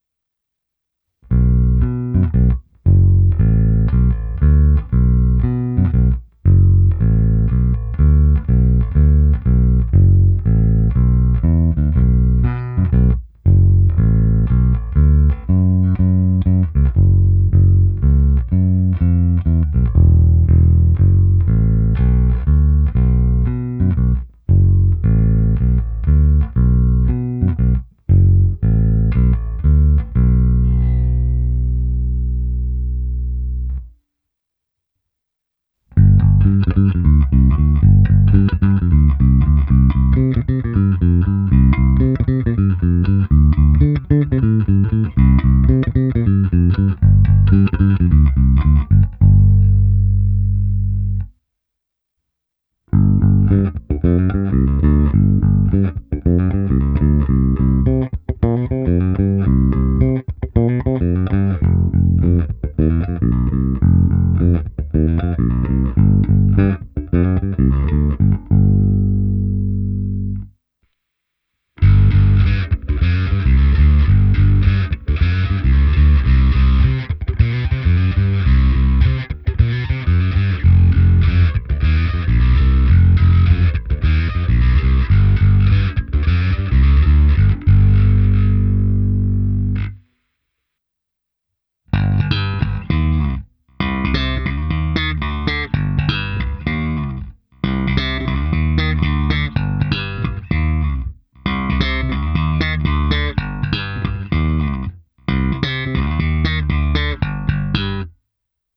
Abych simuloval, jak hraje baskytara přes aparát, protáhnul jsem ji preampem Darkglass Harmonic Booster, kompresorem TC Electronic SpectraComp a preampem se simulací aparátu a se zkreslením Darkglass Microtubes X Ultra. První část ukázky je na krkový snímač, pak následuje ukázka na oba snímače, pak ukázka na kobylkový snímač bez a se zkreslením (tónovou clonu jsem hodně stáhnul) a nakonec slap na oba snímače.
Ukázka se simulací aparátu